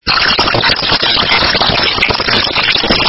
саундтрек из рекламы